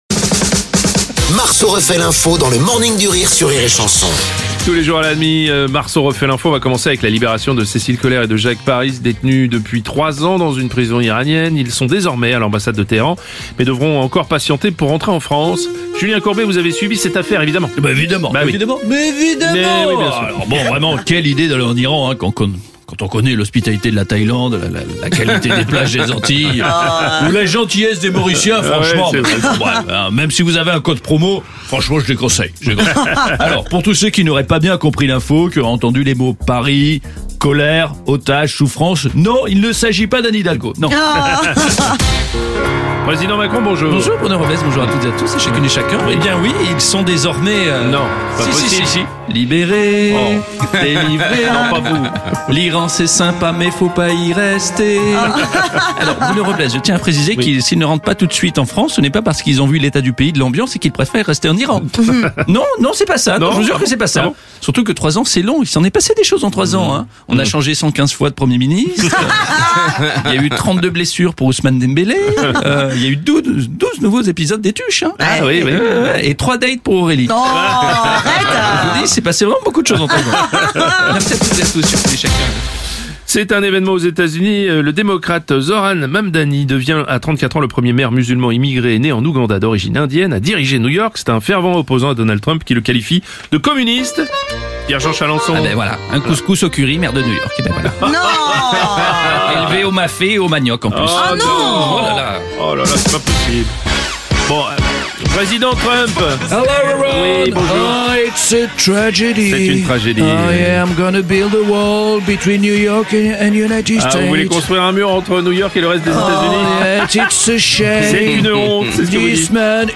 L’imitateur